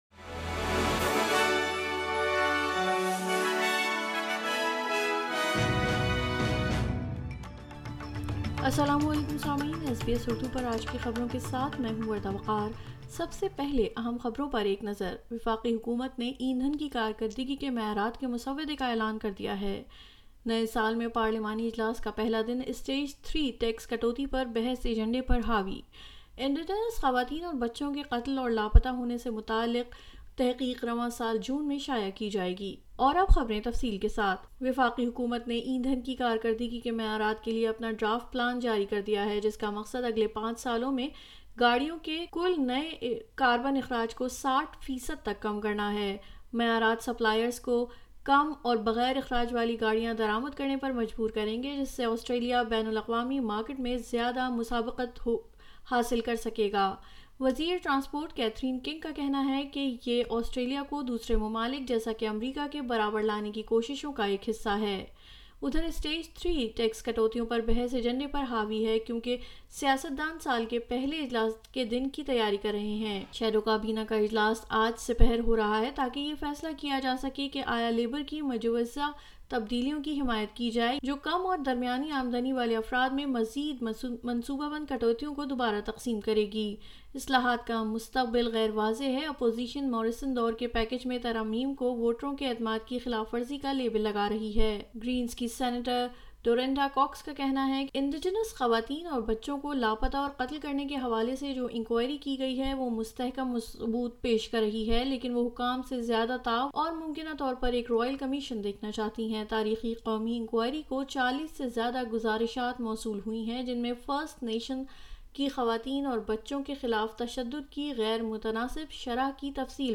نیوز فلیش:05 فروری 2024: مرحلہ تین کی ٹیکس کٹوتی سال کے پہلےپارلیمانی اجلاس کے ایجنڈے میں غالب